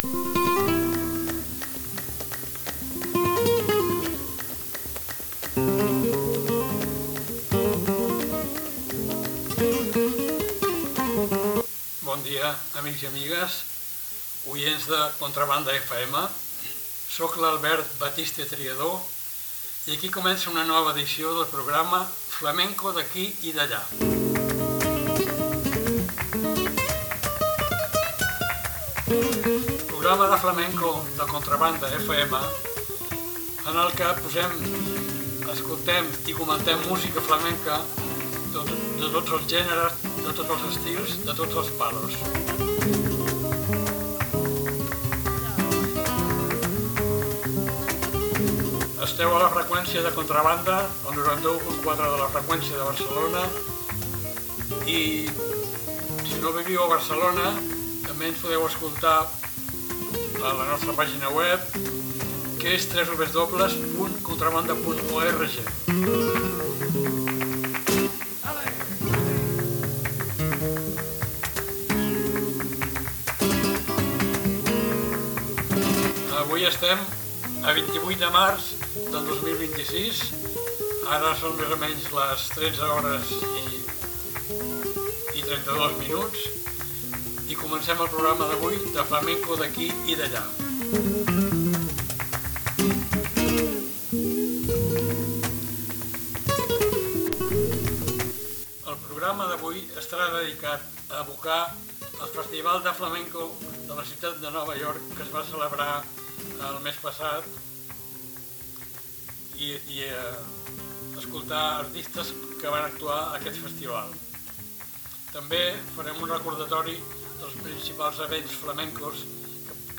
Soleá, Bulerías, Taranta i Araora.
Granaína, Bulerías, Tangos, Rumba i Bulerías.